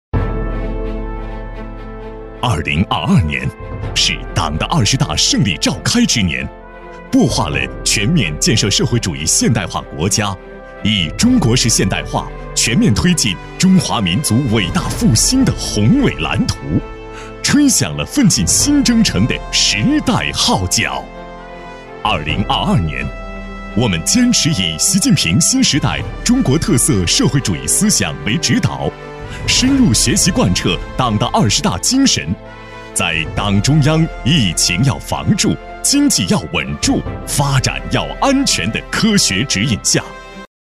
男117-颁奖旁白《回顾2022》-大气有力